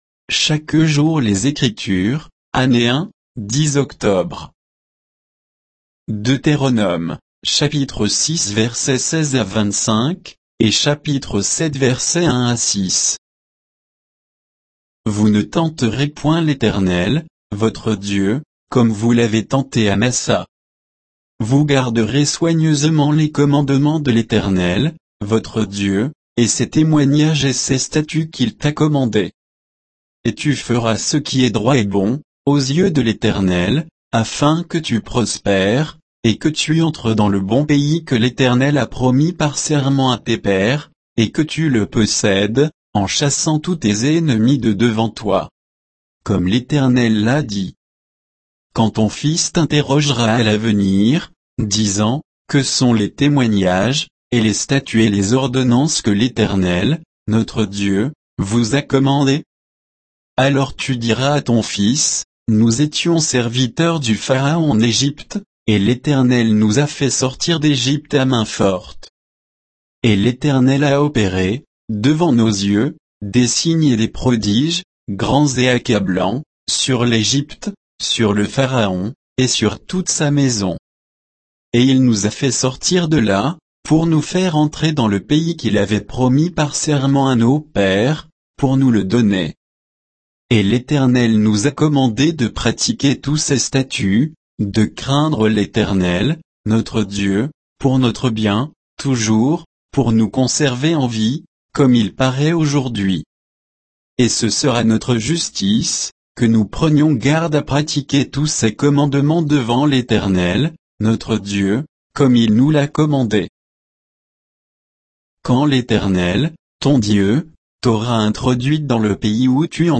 Méditation quoditienne de Chaque jour les Écritures sur Deutéronome 6